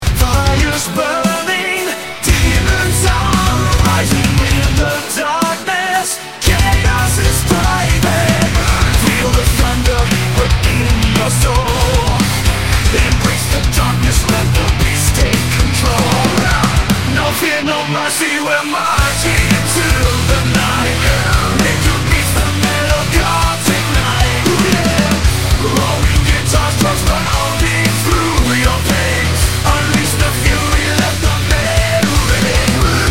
こっち☝はアフター・グランジ的なモダンさが強調されました。なんか、軟弱な感じがして、昭和生まれの感性では、かなりダサいな。